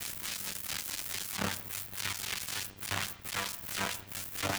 SFX_Static_Electricity_Short_02.wav